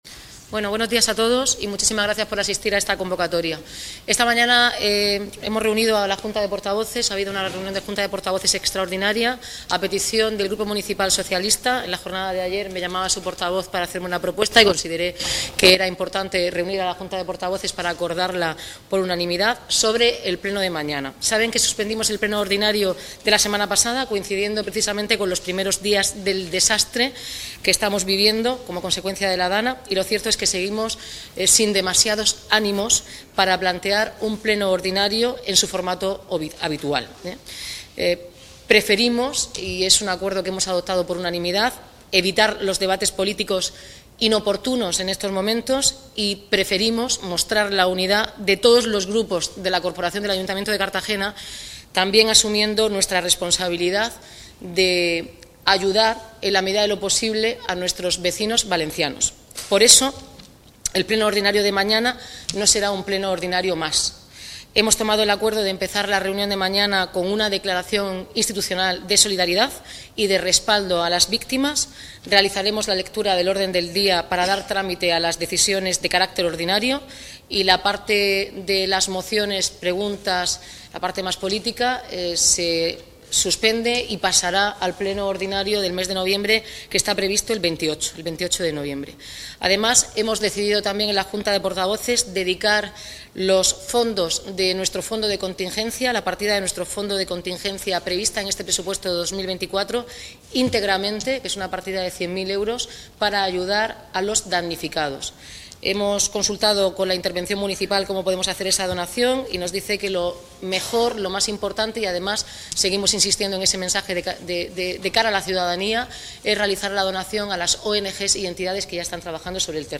Enlace a Declaraciones de la alcaldesa, Noelia Arroyo, y los portavoces Jesús Giménez, Manuel Torre, Gonzalo Pretel y Ana Belén Castejón
Así lo ha comunicado la alcaldesa, Noelia Arroyo, en una comparecencia conjunta con todos los portavoces de los grupos municipales, tras una reunión extraordinaria convocada por la alcaldesa a iniciativa del grupo municipal socialista.